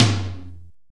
Index of /90_sSampleCDs/AMG - Now CD-ROM (Roland)/DRM_NOW! Drums/TOM_NOW! Toms
TOM JJ TOM 2.wav